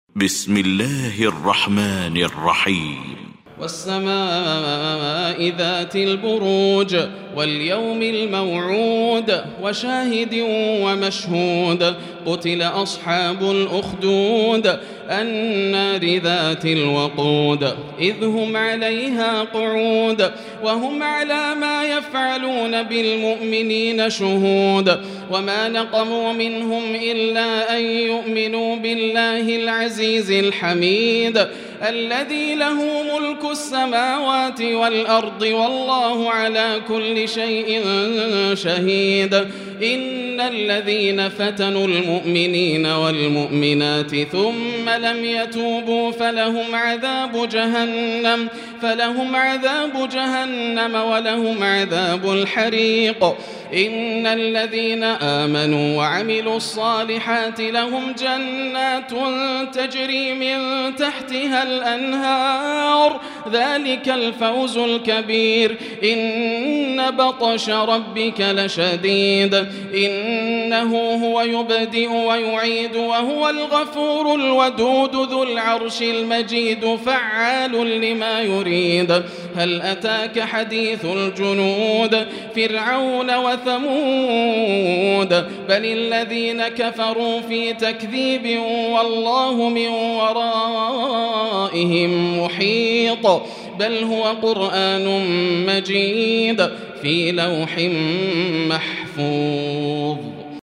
المكان: المسجد الحرام الشيخ: فضيلة الشيخ ياسر الدوسري فضيلة الشيخ ياسر الدوسري البروج